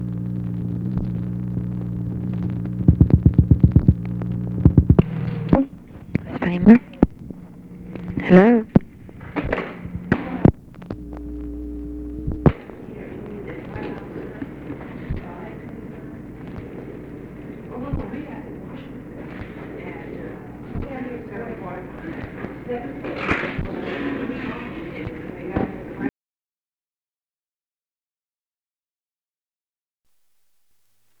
Conversation with OFFICE CONVERSATION
Secret White House Tapes | Lyndon B. Johnson Presidency